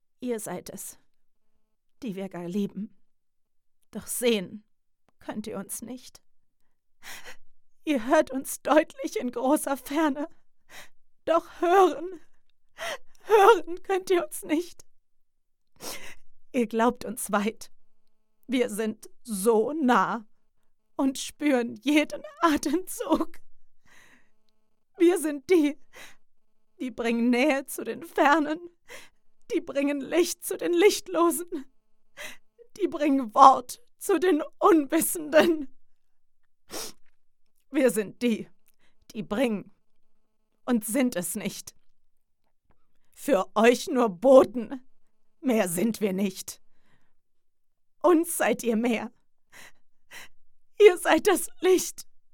traurig download file >>